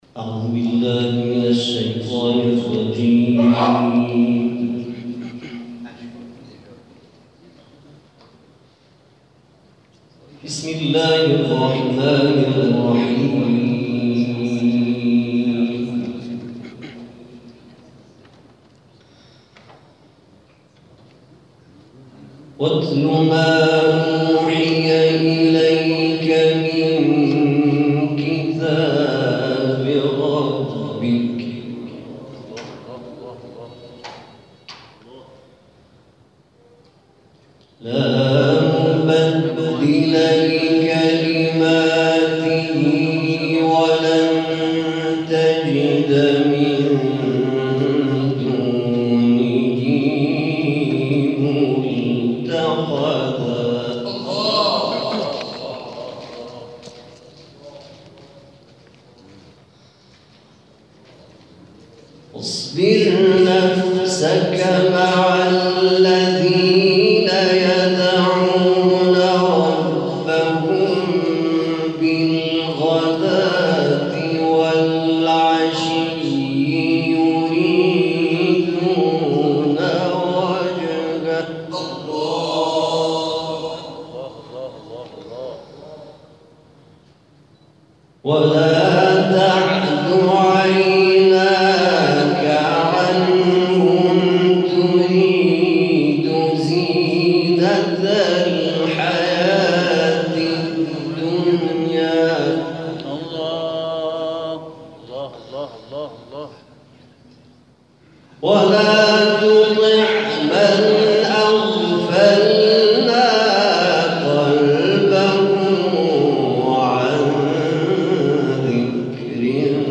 در ادامه تلاوت‌های منتخب این جلسه ارائه می‌شود.